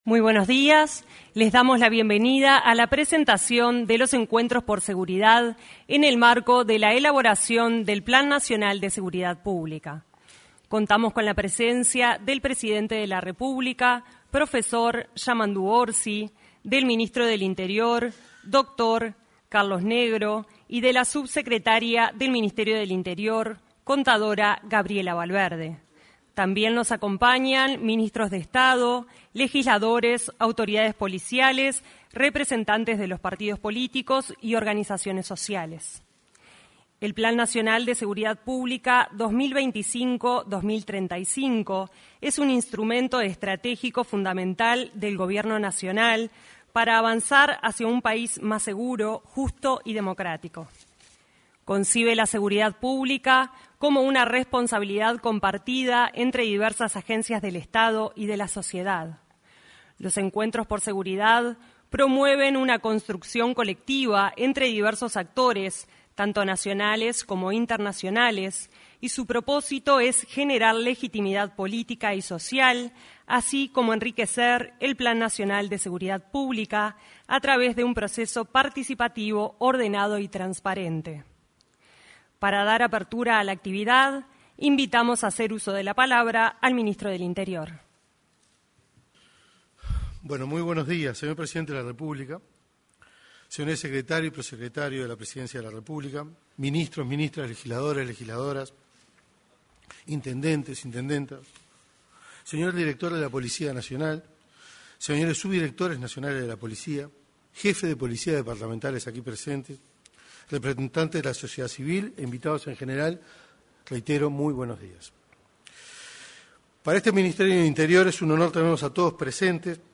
Con motivo del lanzamiento de los Encuentros por Seguridad, se expresaron el ministro del Interior, Carlos Negro, y el asesor en seguridad pública,